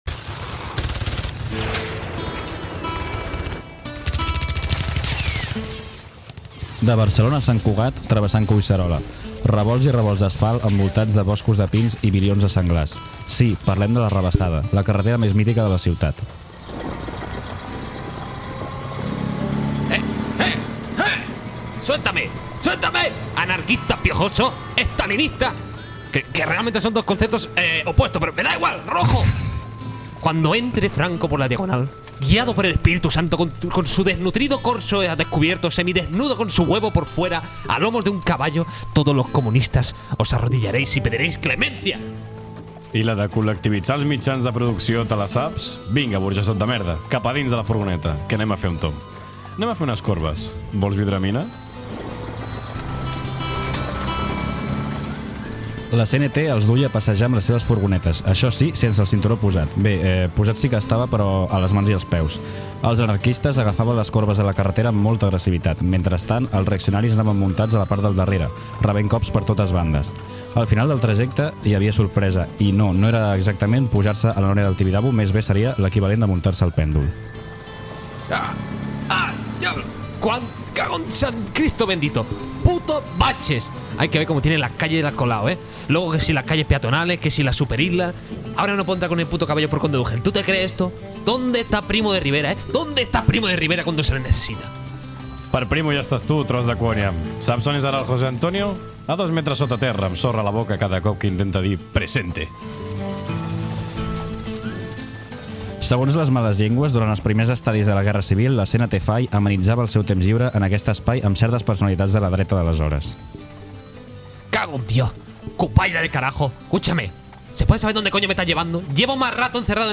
b207a9cccb46bcf26e2c5d8f7440b2e61a8fa8c5.mp3 Títol L'arrabassada (pòdcast) Emissora L'arrabassada (pòdcast) Titularitat Tercer sector Tercer sector Altres Nom programa L'arrabassada Descripció Ficció sonora sobre la carretera de l'Arrabassada i el que hi feia la CNT (Confederació Nacional del Treball), a la Guerra Civil espanyola.